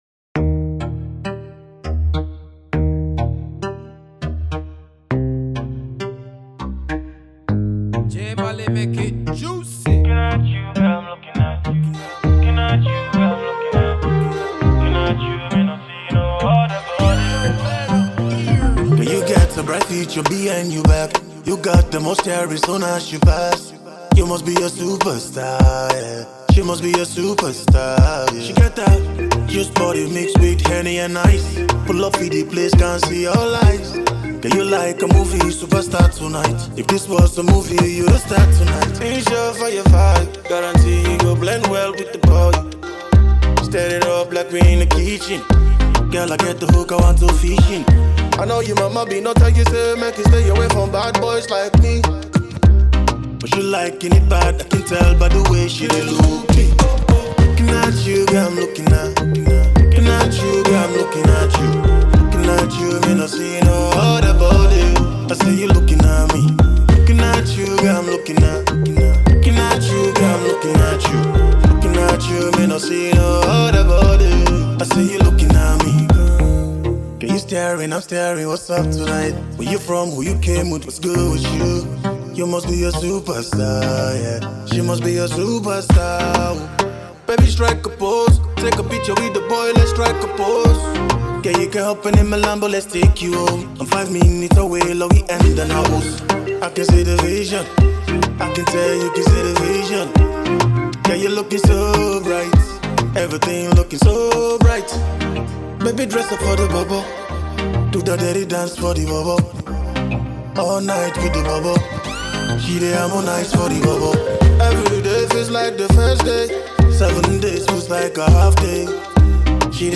masaka afro-pop banger